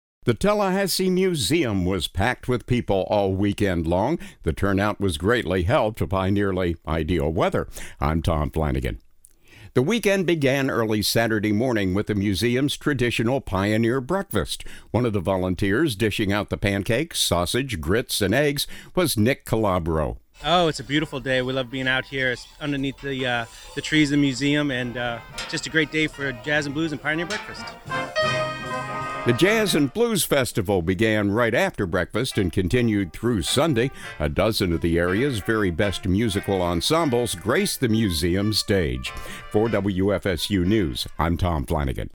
The weekend began early Saturday morning with the museum’s traditional Pioneer Breakfast.
breakfast-jazz0413full.mp3